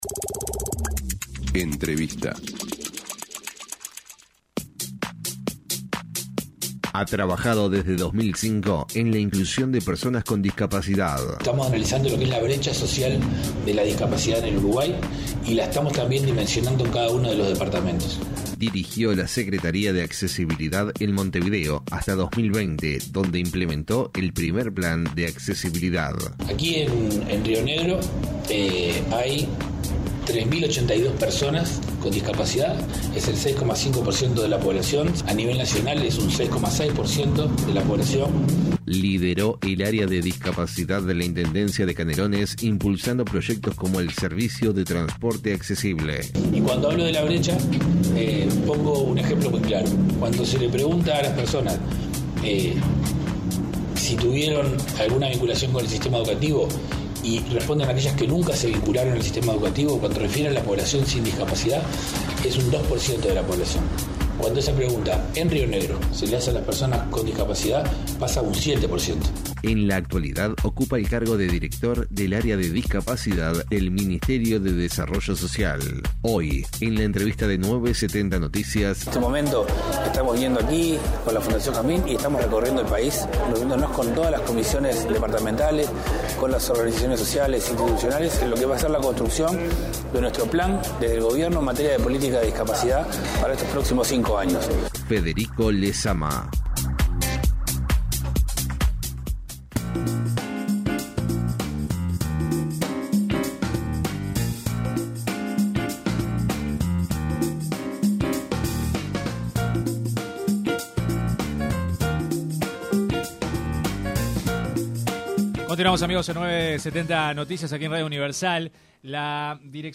El director del Área de Discapacidad del Ministerio de Desarrollo Social (MIDES), Federico Lezama en entrevista con 970 Noticias señaló sus críticas respecto a las modificaciones que se plantean en el Parlamento sobre la Ley de Protección Integral para las Personas con Discapacidad.